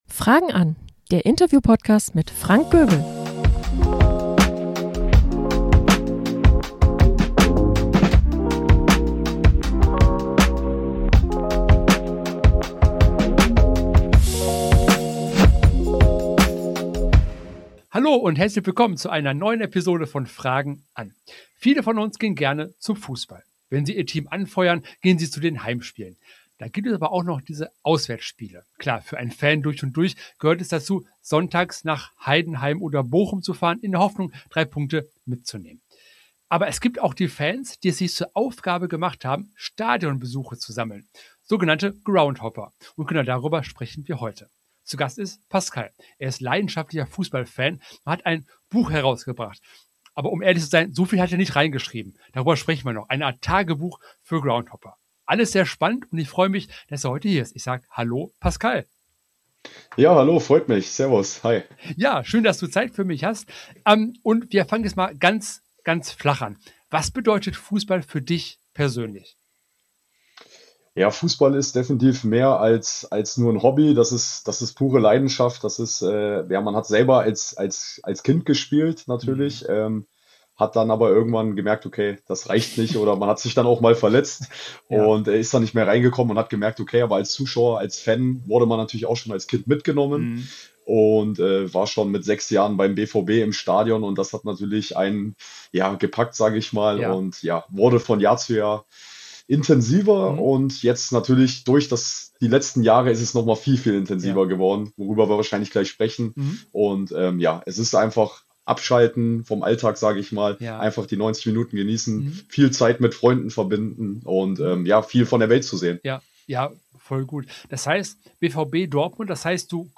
Ich lade dich zu einem sehr angenehmen und lustigen Gespräch ein +++++++++++++++++++++++++++++++ Jede Folge entsteht mit viel Zeit, Neugier und echter Lust auf gute Gespräche.